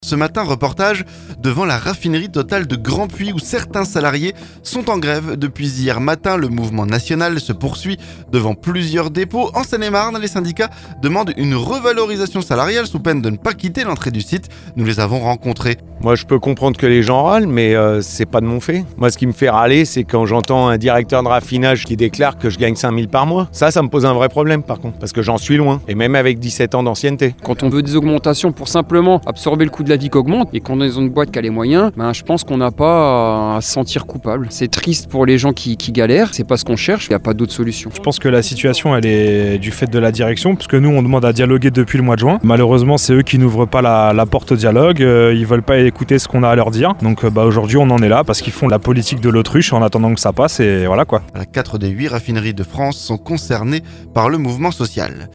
Reportage ce mercredi devant la raffinerie Total de Grandpuits.